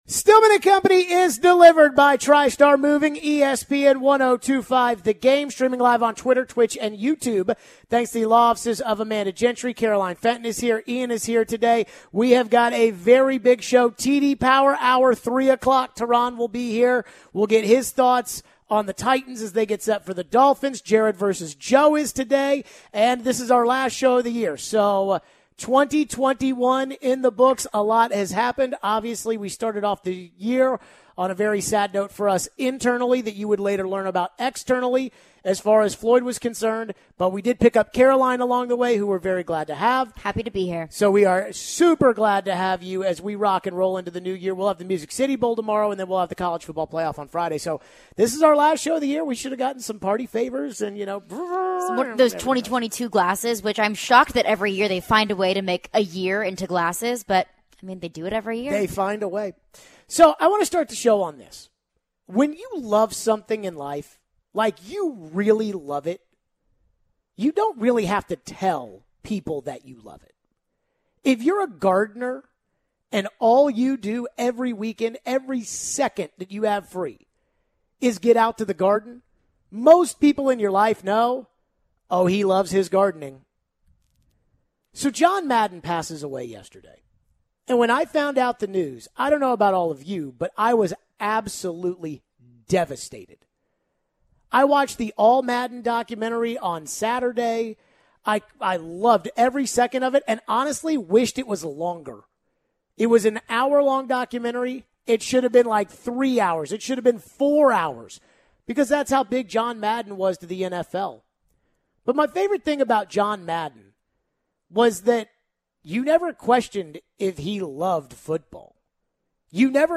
We take your phones. Plus our weekly visit with forever Titans WR Chris Sanders.